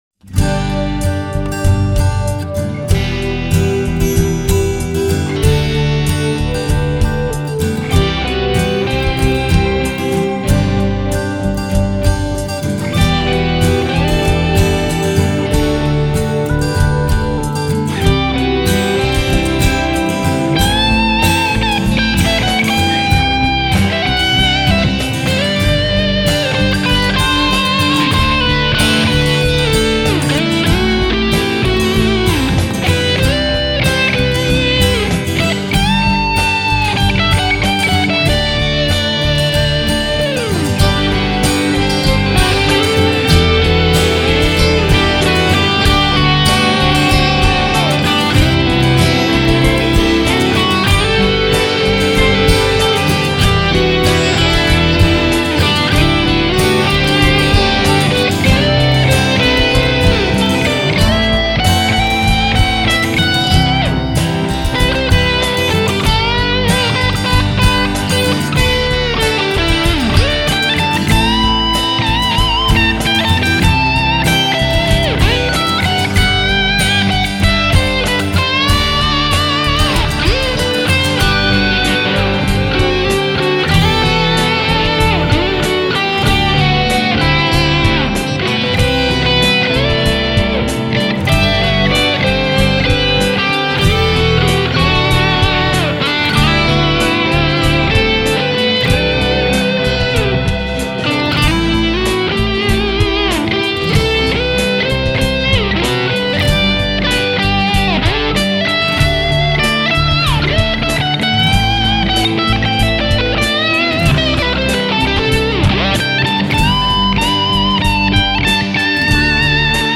Aber irgendwie packte mich die Lust und ich dachte, wenn wir eh schon ne Wall of Sound haben, dann aber richtig und habe zu den fünf schon vorhandenen Gitarrenspuren nochmal fünf zusätzliche eingespielt.
Benutzt habe ich für sämtliche Spuren dieselbe Gitarre, die ich auch für meine Spur im Backing vorher benutzt hatte, meine alte MelodyMaker mit dem Womanizer.